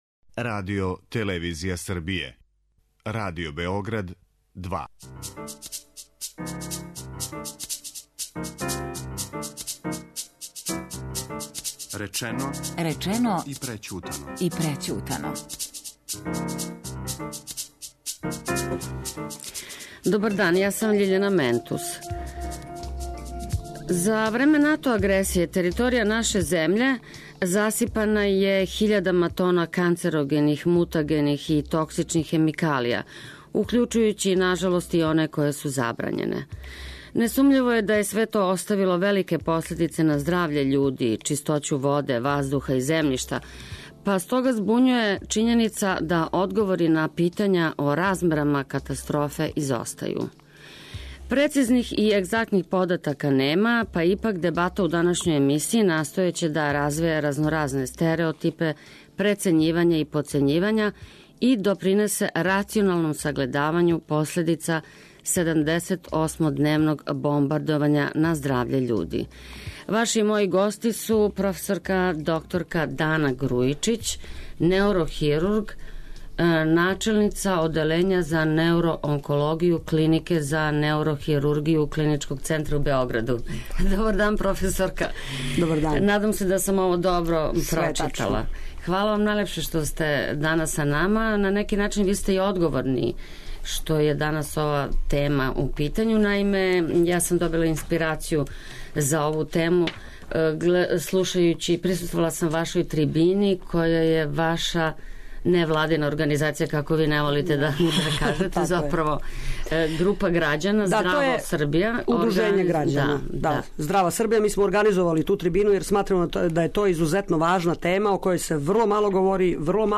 Прецизних, егзактних података нема, па ипак, дебата у данашњој емисији настојаће да развеје разноразне стереотипе, прецењивања и потцењивања и допринесе рационалнијем сагледавању последица 78-дневног бомбардовања пре 16 година...